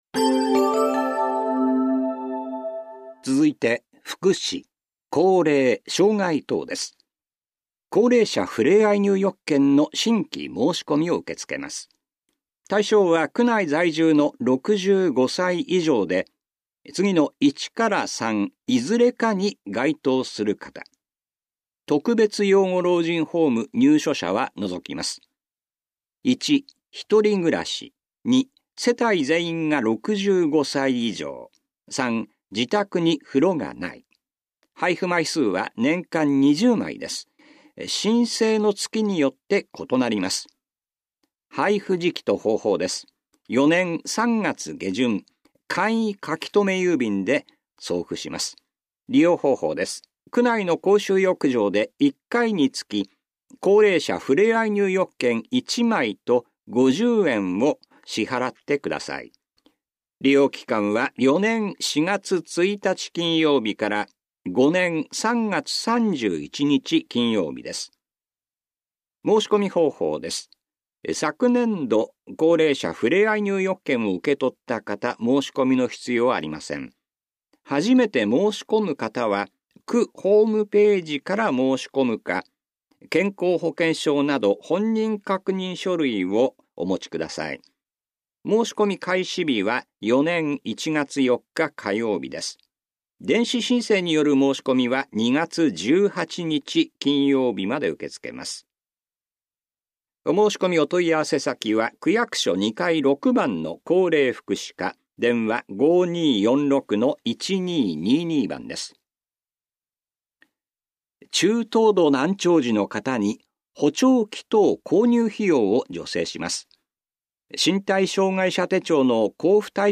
広報「たいとう」令和3年12月20日号の音声読み上げデータです。